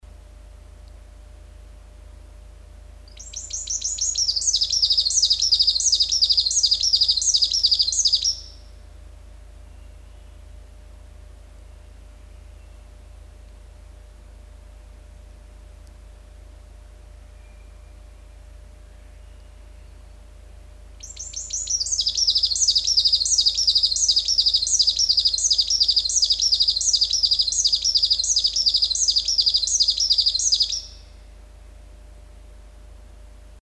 Mahdollinen viirusirkka määrittyi helposti viitasirkkalinnuksi, mutta se vieressä lauloi todella oudonkuuloinen lintu! Mutta kyllä sekin oli selvä viitasirkkeli, vaikkei sitä näkyviin saatukaan.
Outo viitasirkkalintu -äänitys – Ruokolahden ja Rautjärven läpi palailin pikkuteitä havaiten muutaman hirven, pari kehrääjää sekä teeren.